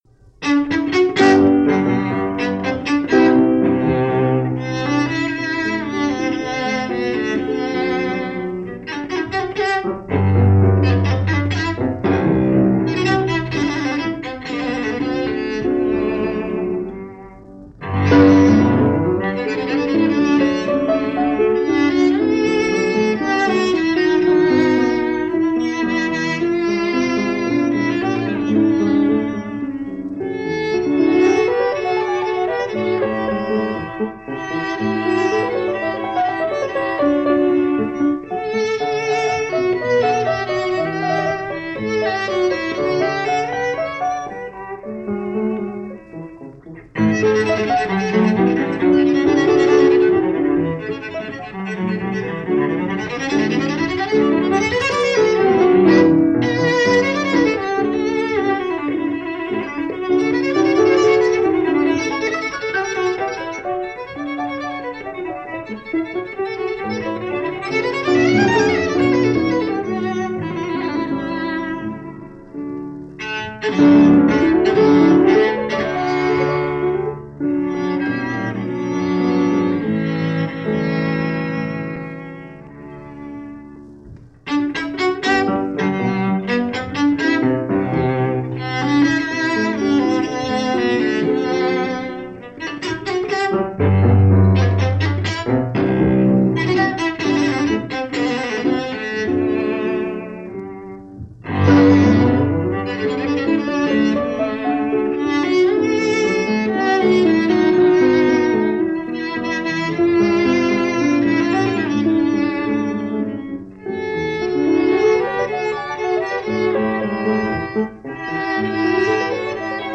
recorded at ORTF Studios, Paris
Jean-Baptiste Bréval – Sonata In G Major – André Navarra, Cello
Piano
Historic musicians in less-than-familiar Baroque music this weekend.
They play Bréval’s Sonata in G Major for Cello and Harpsichord (transcribed for piano for this broadcast).
Cellist André Navarra – respected practitioner of the French school of string playing.